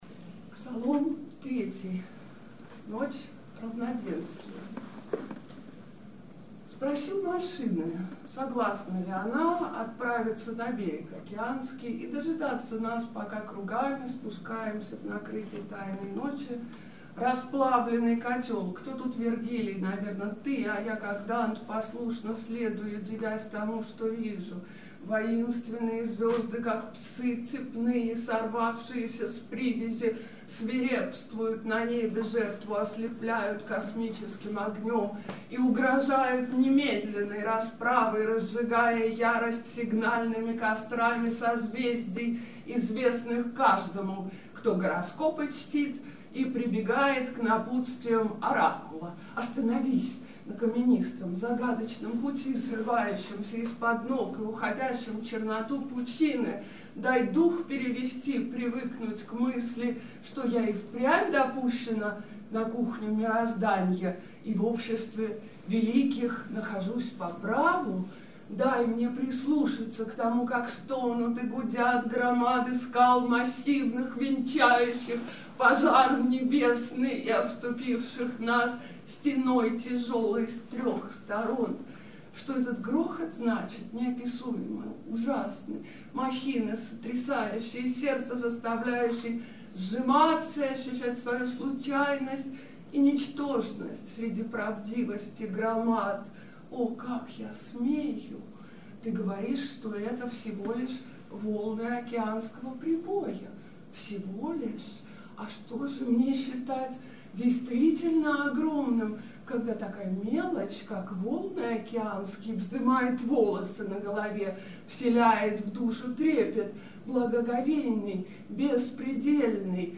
Авторское чтение: